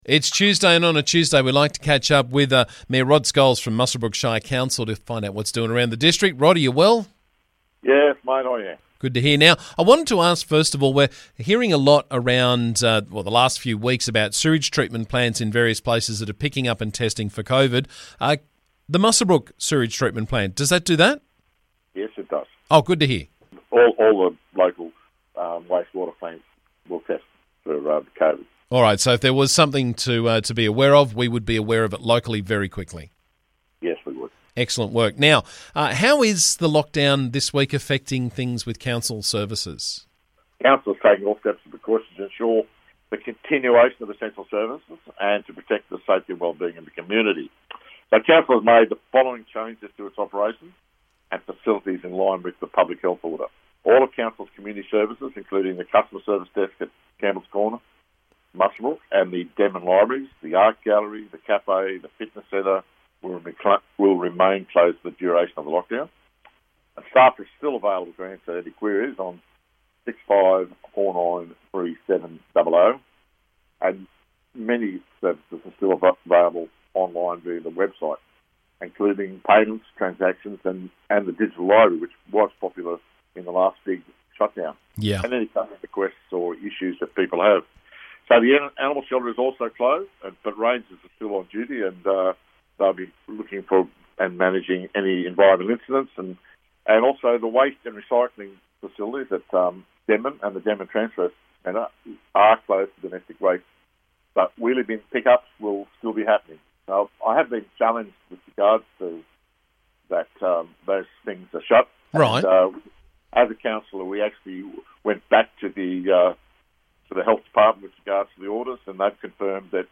Muswellbrook Shire Council Mayor Rod Scholes joined me to talk about the latest from around the district.